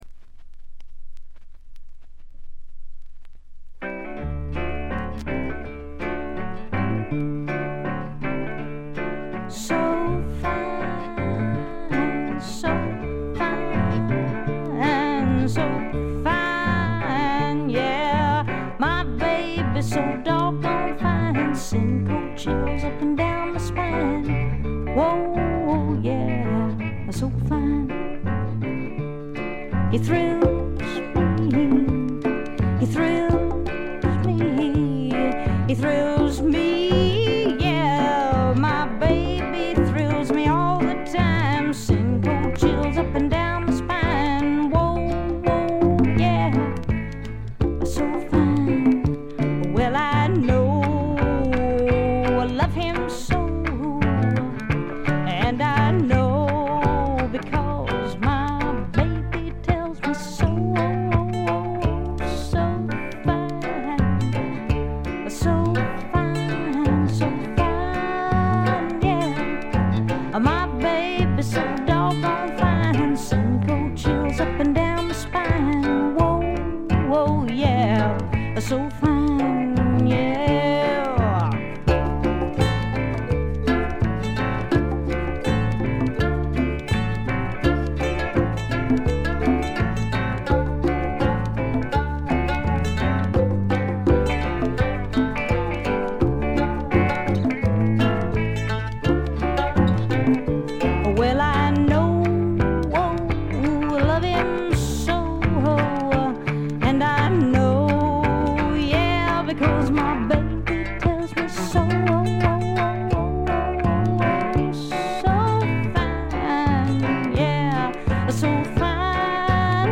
バックグラウンドノイズがほぼ常時出ており静音部でやや目立ちます。
試聴曲は現品からの取り込み音源です。
Guitar, Harmonica, Vocals